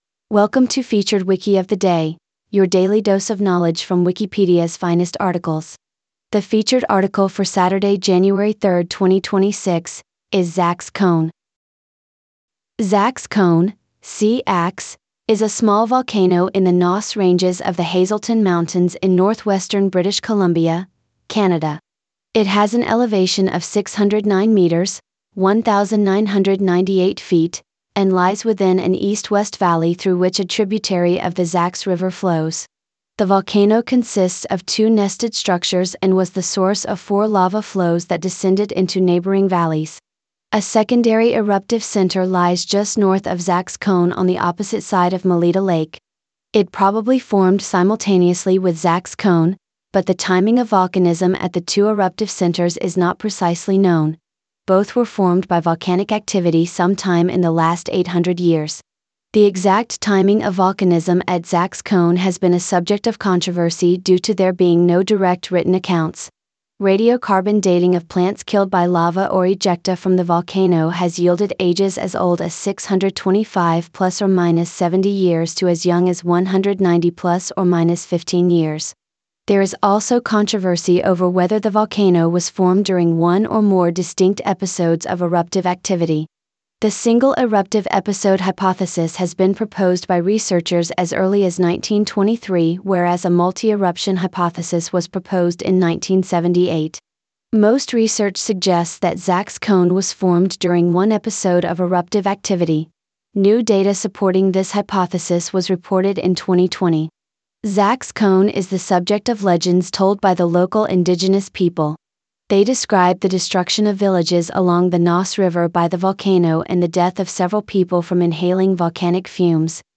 The featured article for Saturday, 3 January 2026, is Tseax Cone. Tseax Cone ( SEE-aks) is a small volcano in the Nass Ranges of the Hazelton Mountains in northwestern British Columbia, Canada.